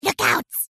Tags: Everquest 2 Ratonga emote Heals me I dont think soes